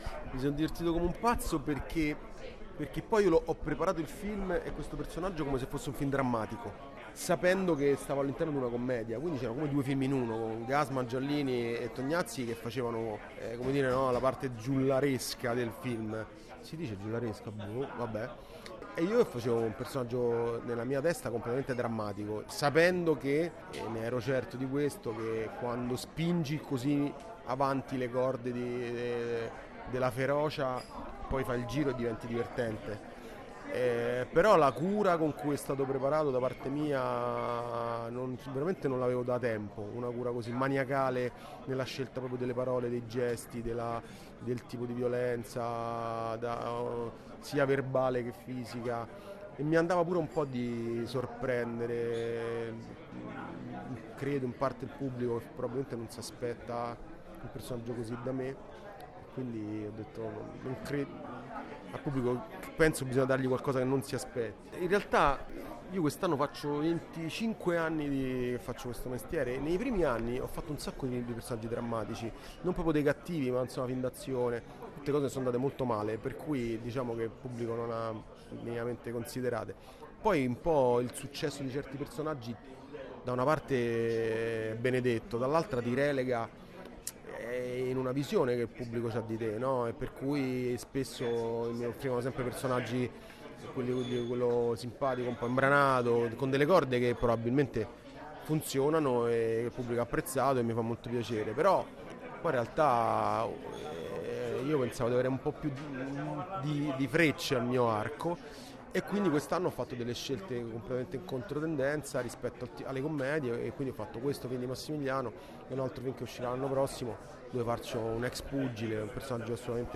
non-ci-resta-che-il-crimine-edoardo-leo-parla-del-suo-ruolo.mp3